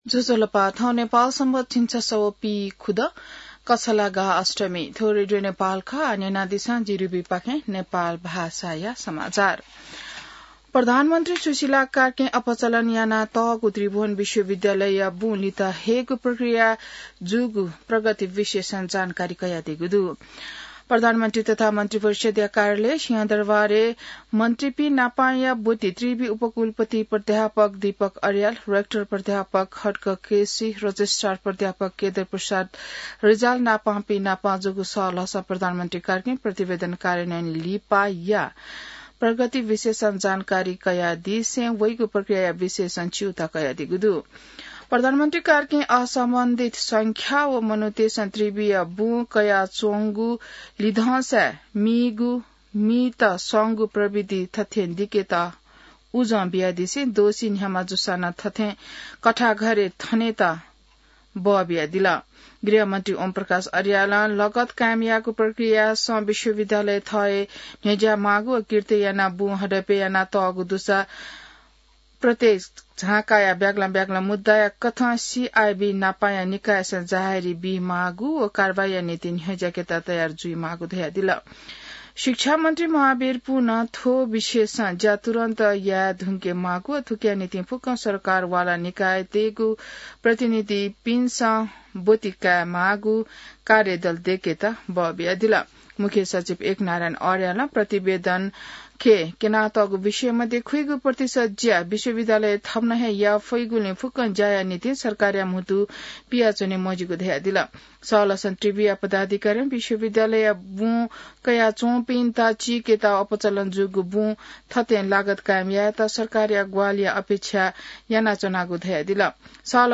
नेपाल भाषामा समाचार : २६ कार्तिक , २०८२